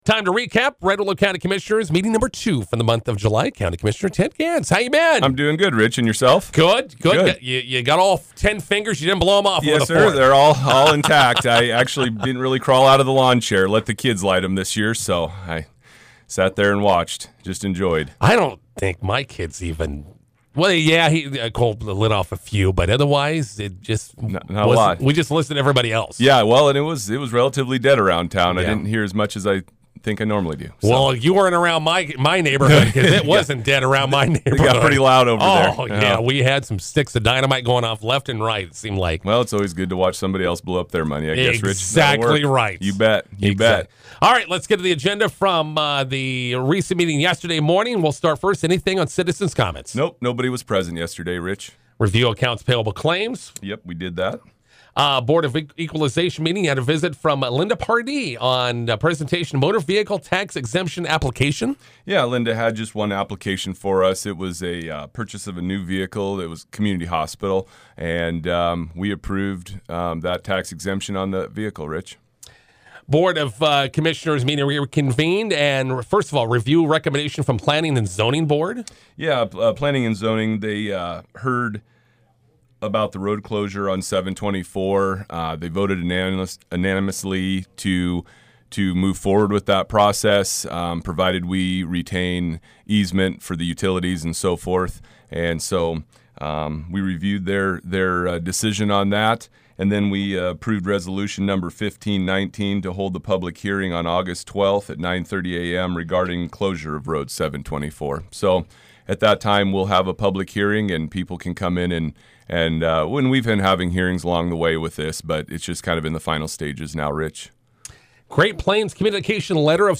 INTERVIEW: Red Willow County Commissioners meeting recap with County Commissioner Ted Gans.